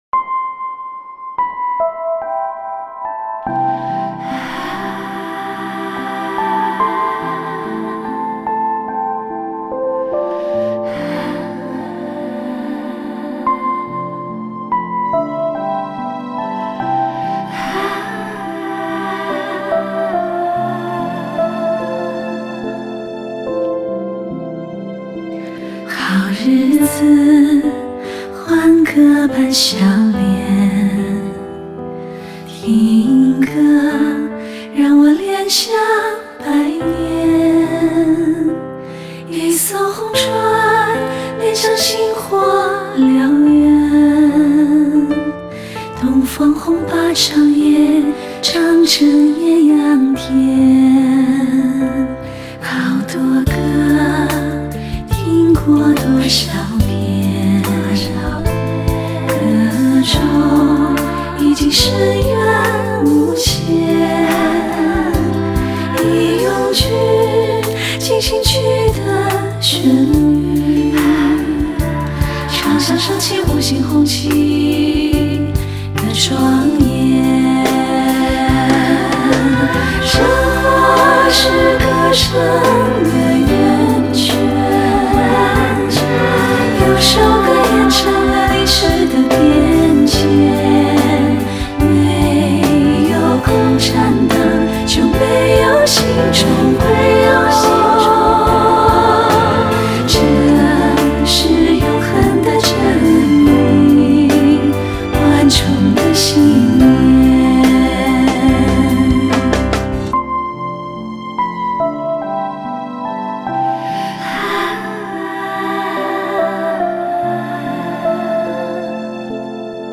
中国最早的和声组合之一
其演唱组最擅长的是以优美和谐的声音叩开心扉，演唱风格以情带声，正规的音乐教育和系统的专业训练，培养了她们良好的音乐素养。